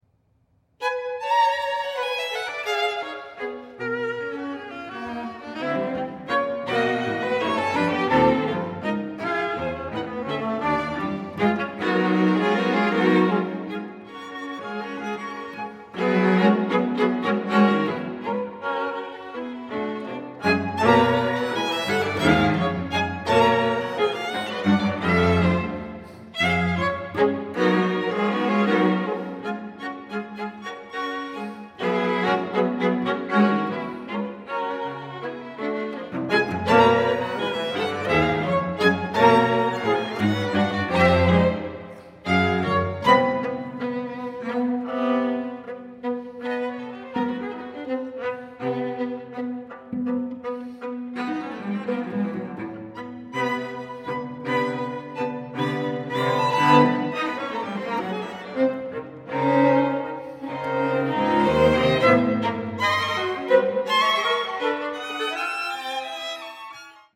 Longtime Duke University string quartet in residence